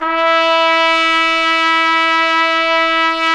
Index of /90_sSampleCDs/Roland LCDP12 Solo Brass/BRS_Tpt _ menu/BRS_Tp _ menu